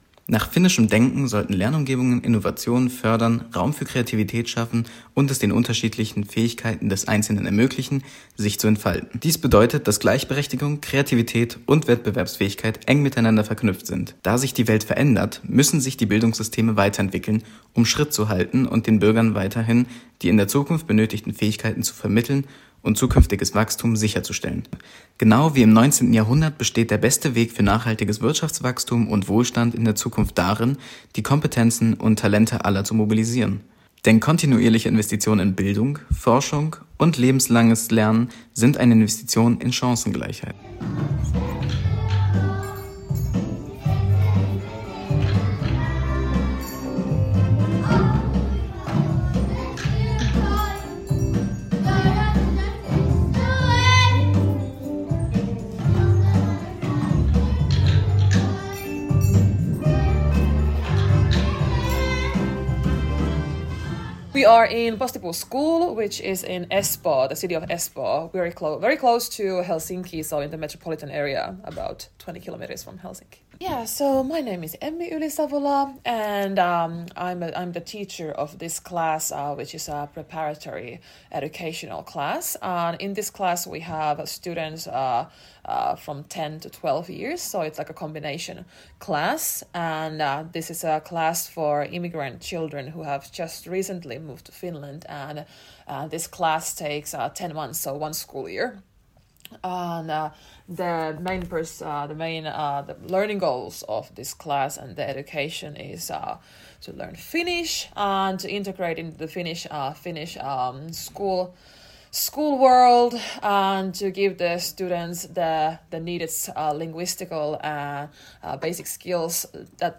Young authors and a teacher will report on it in the Alex Media studio, where our program will also be broadcast.